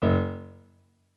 MIDI-Synthesizer/Project/Piano/10.ogg at 51c16a17ac42a0203ee77c8c68e83996ce3f6132